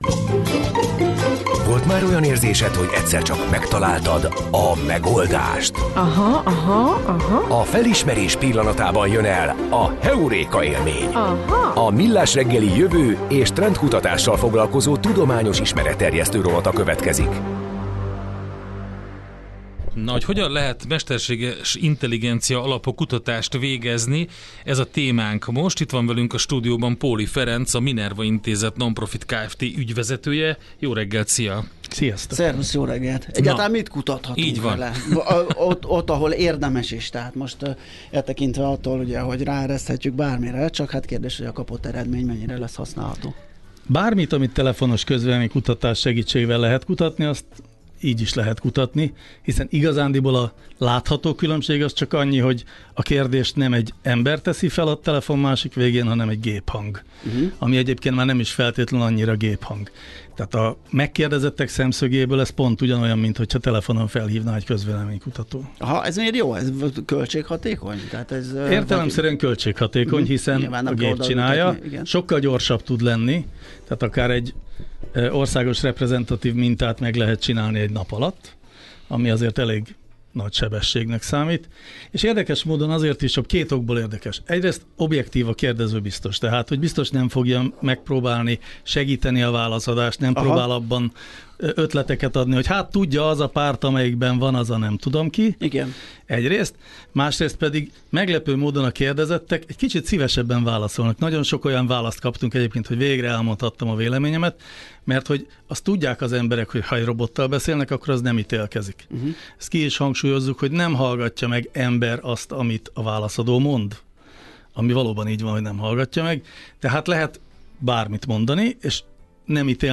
Millásreggeli: interjú2025. augusztus 11.